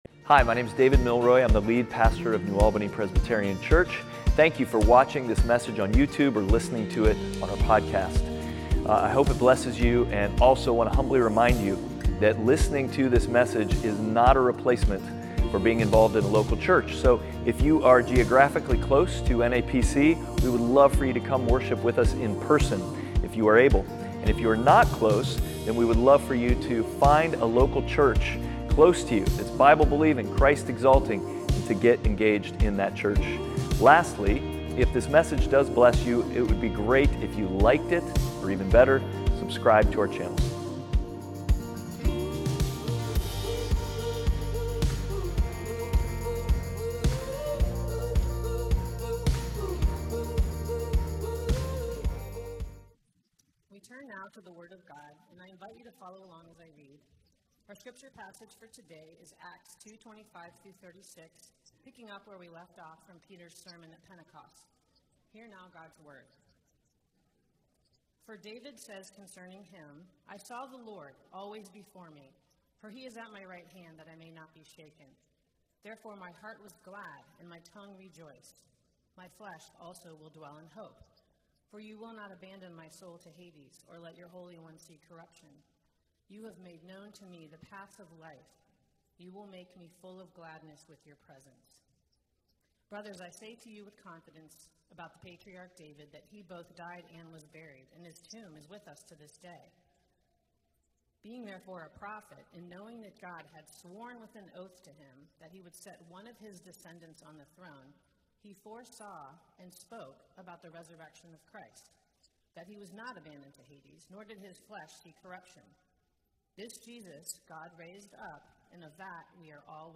Outward: First Sermon Ever Part 2